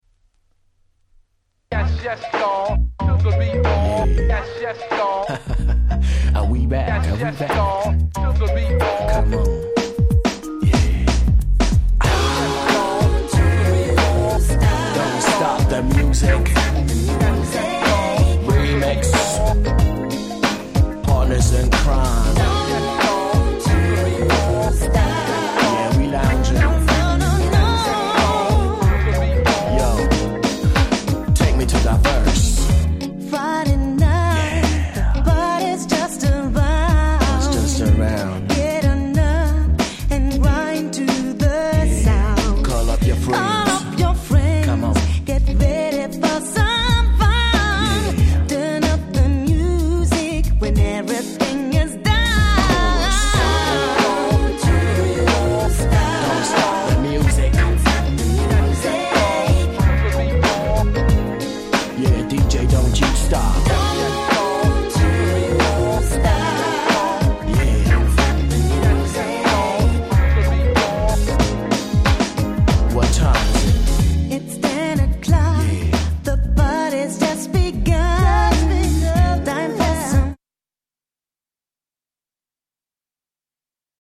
詳細不明のドイツ産良質R&B！！
使いの超絶美メロMid♪